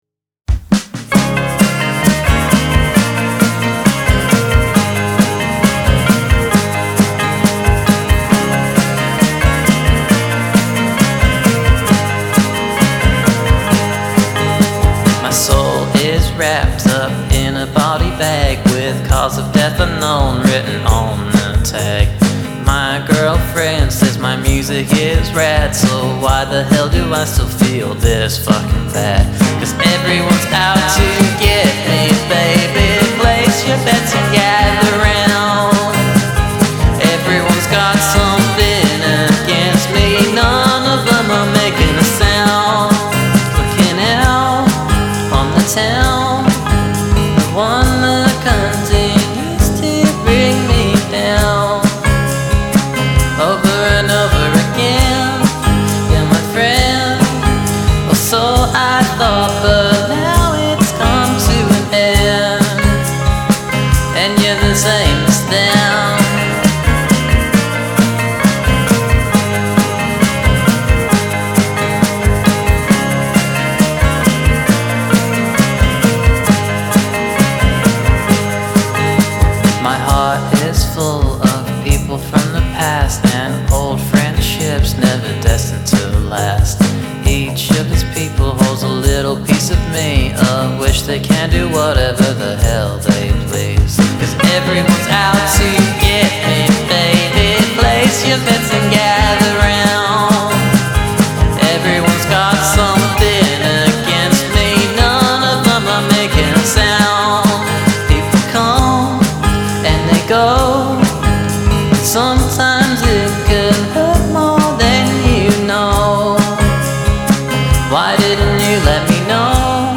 an indie lo-fi diamond bursting with melody
infectious intro riff & upbeat happy feel